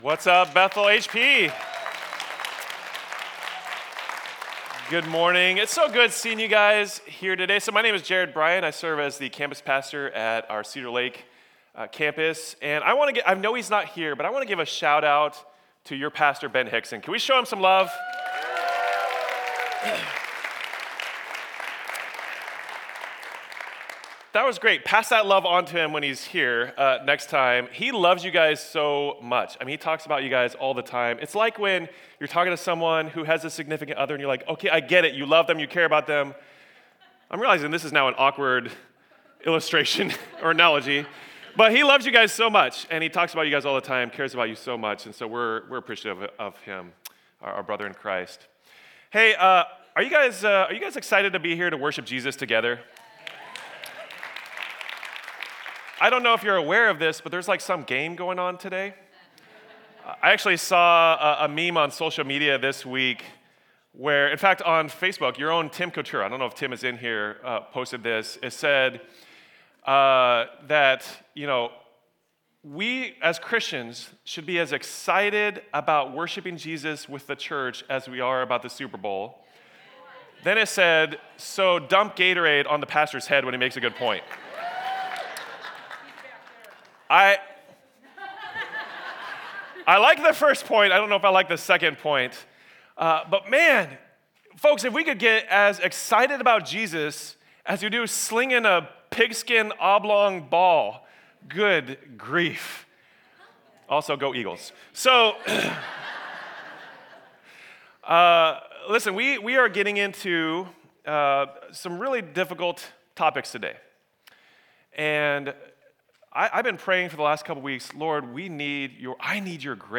Image of God in Early Life | Image of God - HP Campus Sermons